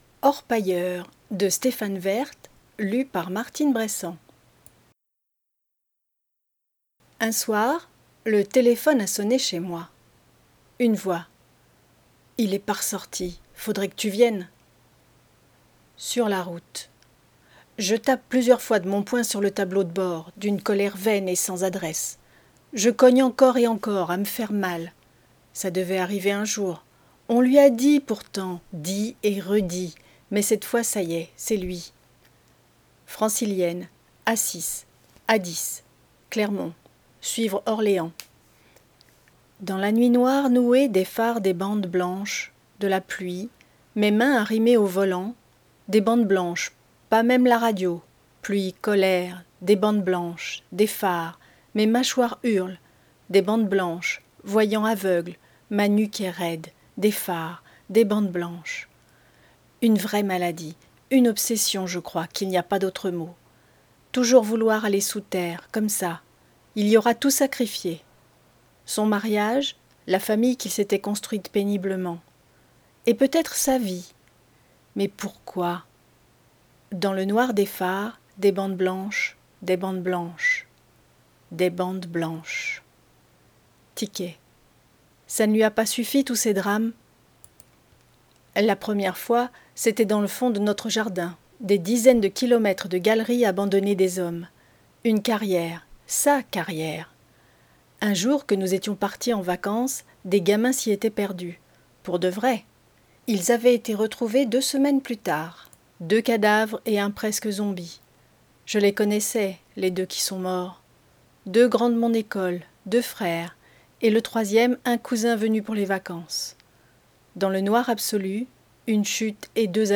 NOUVELLE